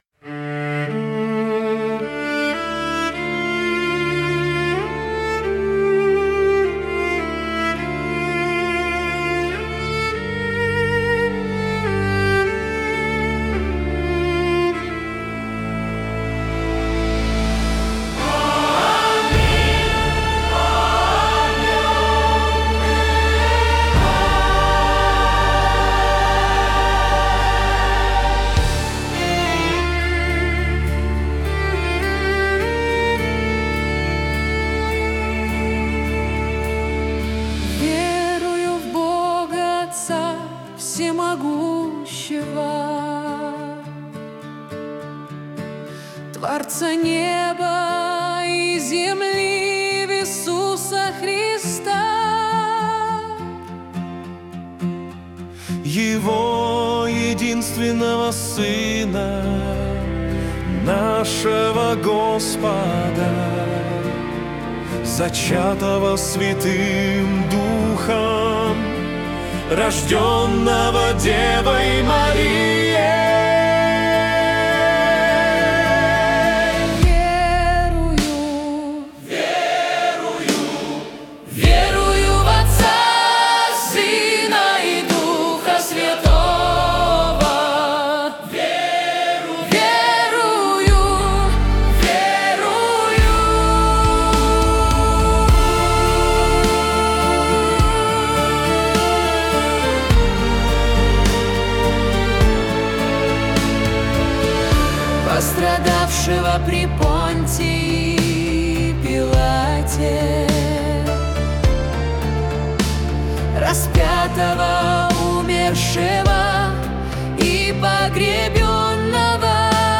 песня ai
177 просмотров 790 прослушиваний 43 скачивания BPM: 104